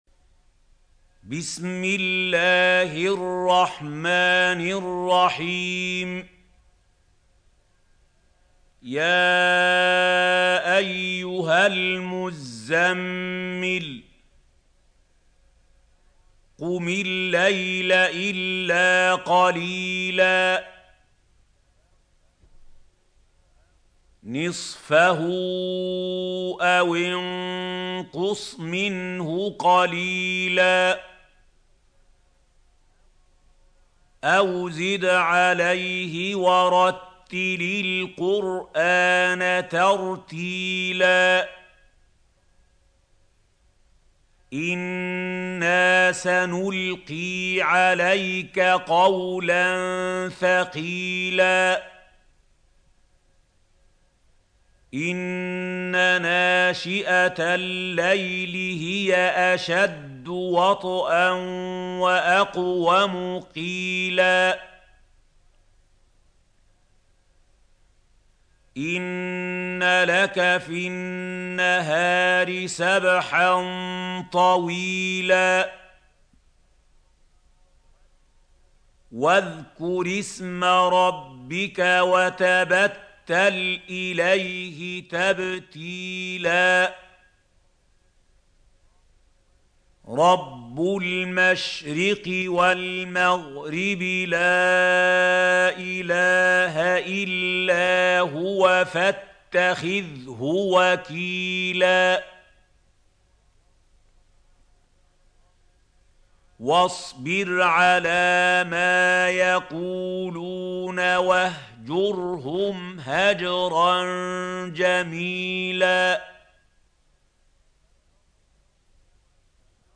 سورة المزمل | القارئ محمود خليل الحصري - المصحف المعلم